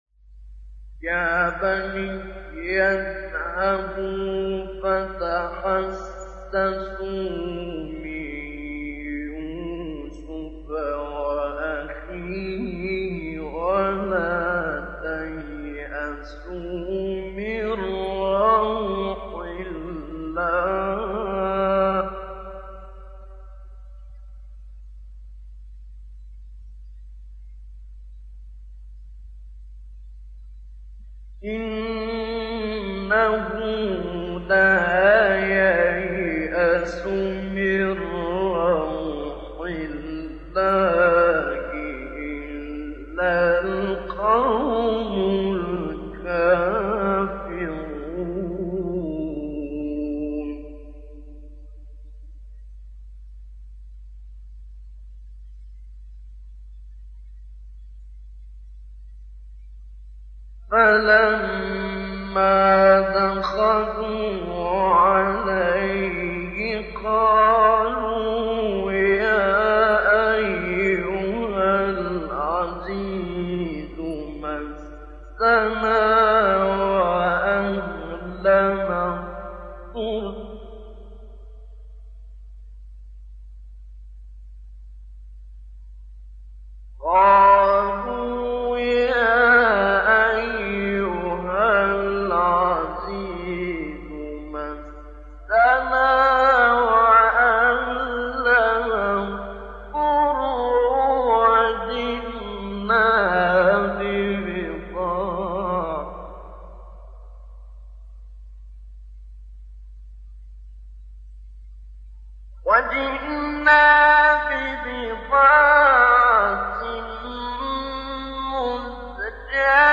سوره : یوسف آیه: 87-91 استاد : ابوالعینین شعیشع مقام : مرکب‌خوانی ( بیات * صبا * بیات) قبلی بعدی